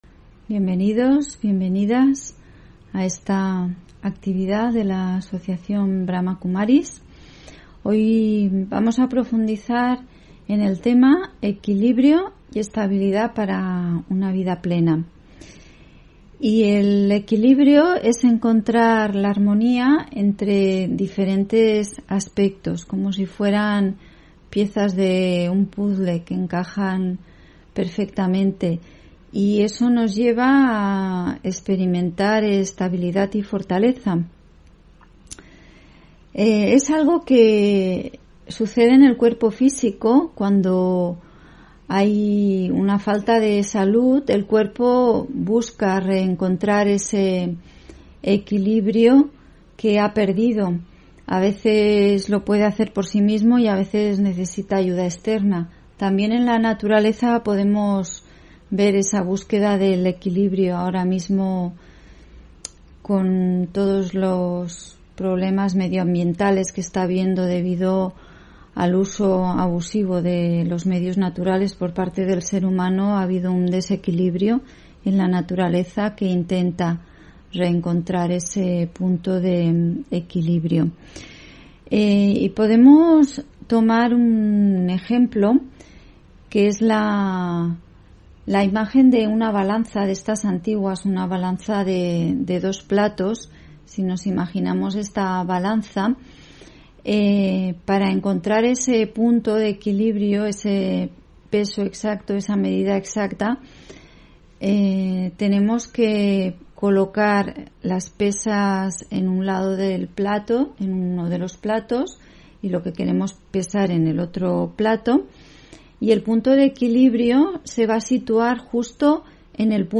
Meditación y conferencia: Equilibrio y estabilidad para una vida plena (9 Septiembre 2021)